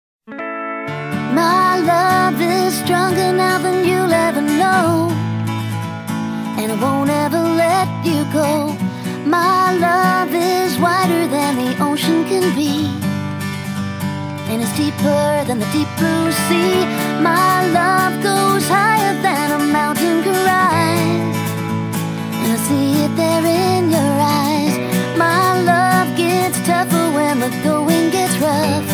Gattung: Moderner Einzeltitel
B-C Besetzung: Blasorchester PDF